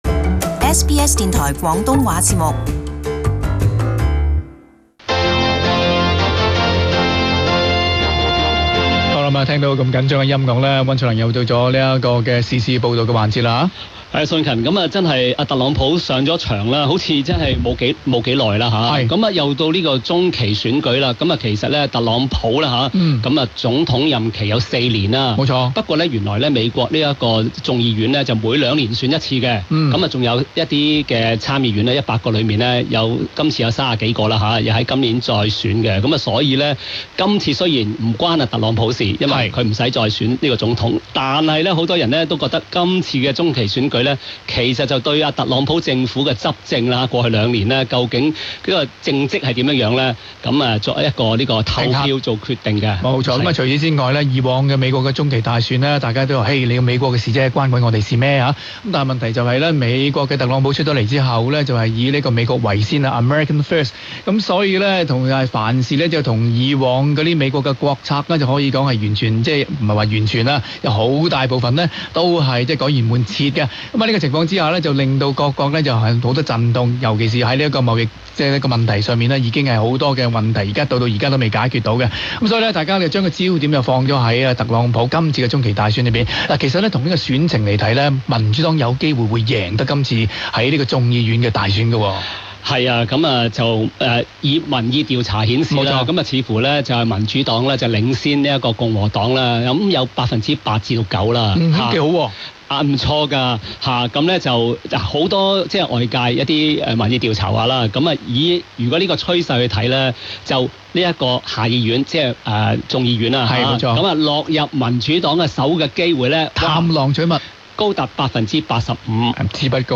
【時事報道】各國關注美國中期大選結果